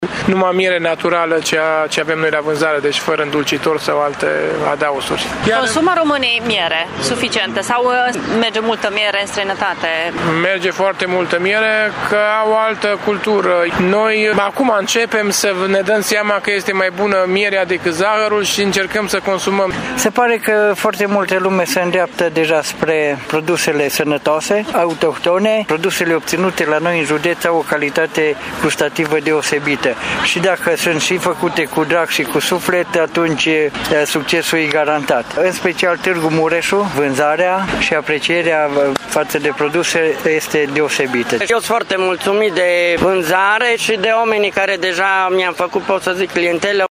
Aceștia spun că de circa un an și jumătate, apetitul pentru produsele alimentare tradiționale a crescut iar oamenii încep să aprecieze tot ce este bio: